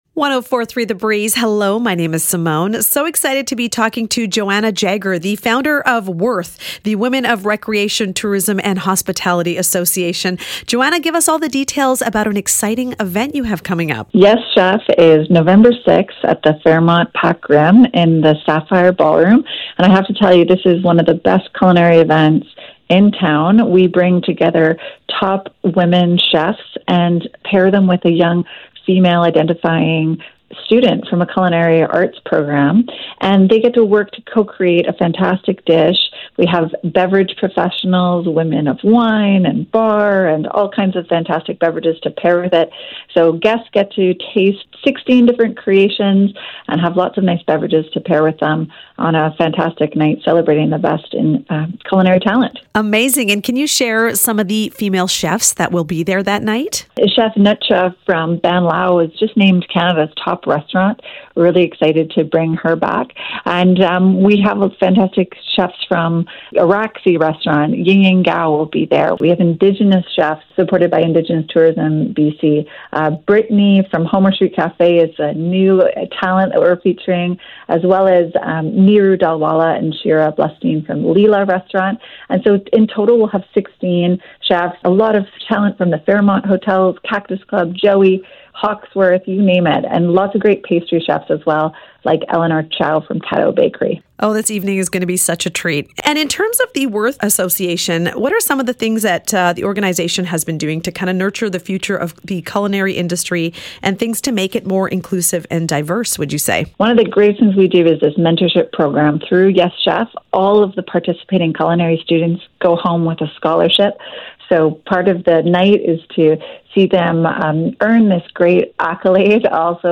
blog-interview.mp3